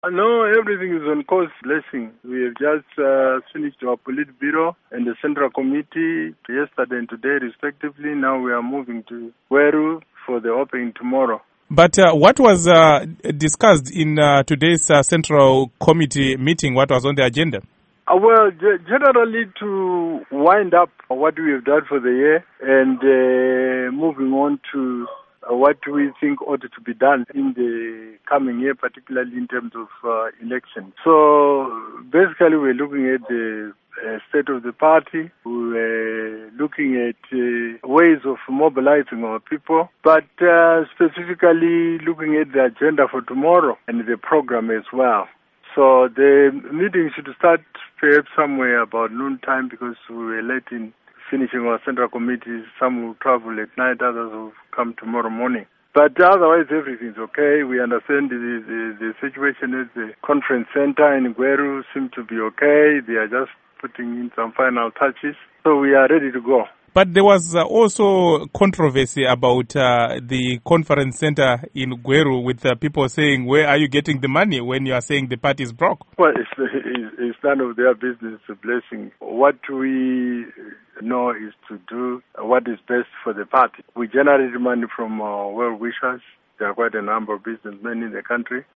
Interview with Rugare Gumbo